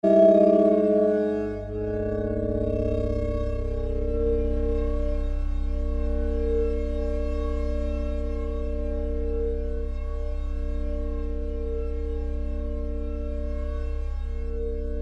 Warble_Hum_01-1-sample.mp3